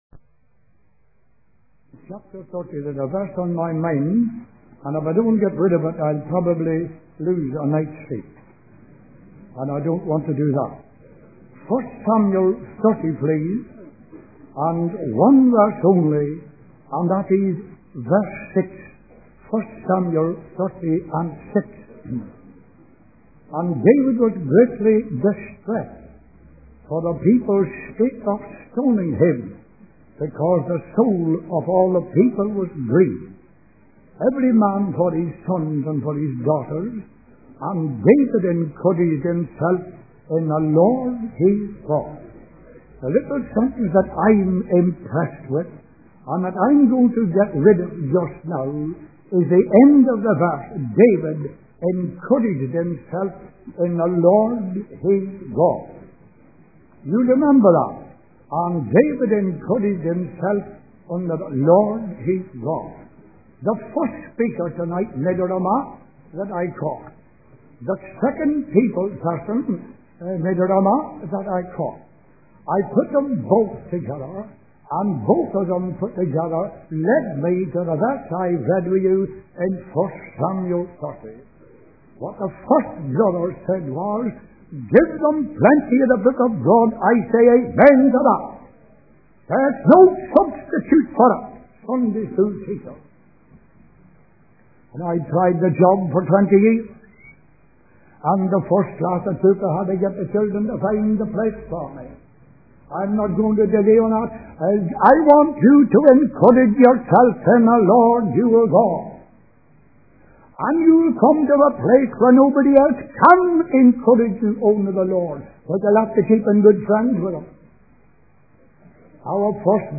In this sermon, the speaker emphasizes the importance of teaching the word of God to children. He encourages teachers to love, pray, and live with their students, guiding them towards salvation. The speaker also advises against introducing new songs or ideas, instead focusing on the timeless truths of the Bible.